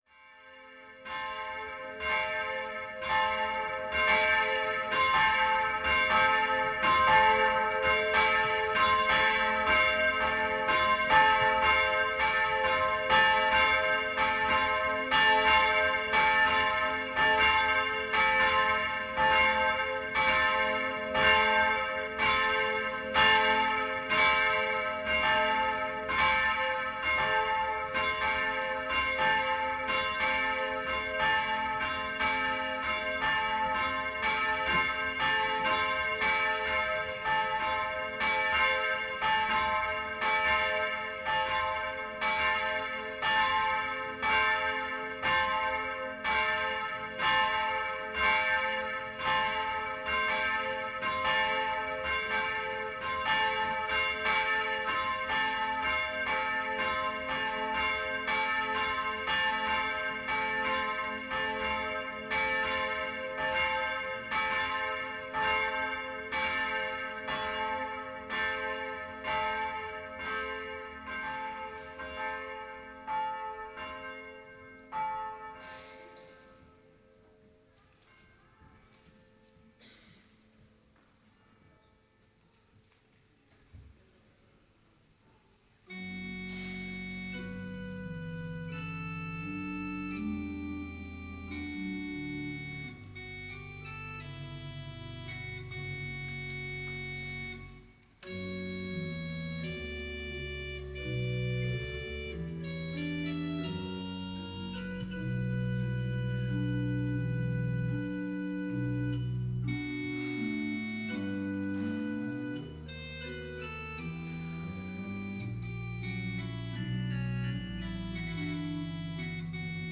Útvarpið sendir beinleiðis gudstænastu hvønn sunnudag í árinum úr føroysku fólkakirkjuni.
Sagt verður, hvør er prestur, deknur, urguleikari og klokkari, og hvørjir sálmar verða sungnir.